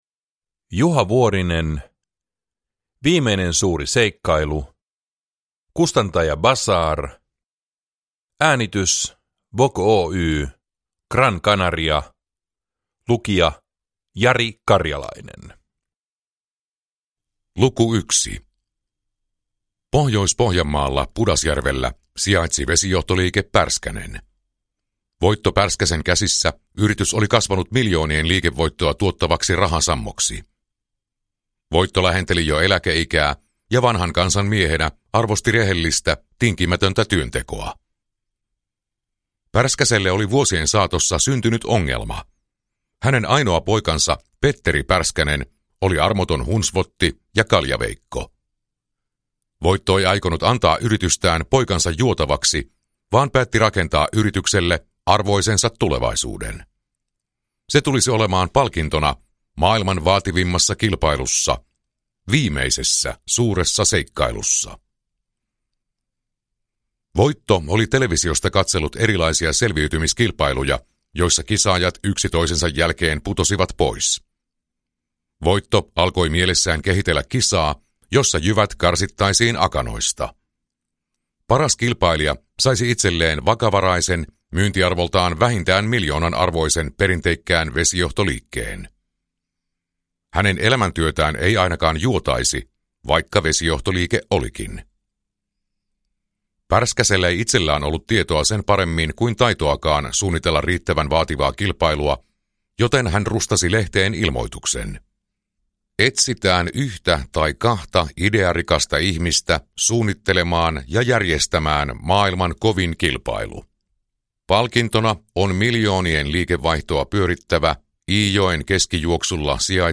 Viimeinen suuri seikkailu – Ljudbok